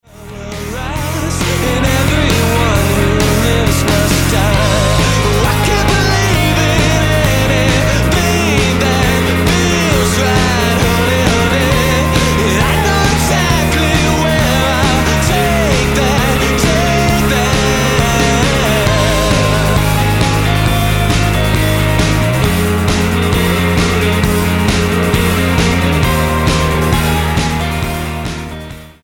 emo rockers
Rock Album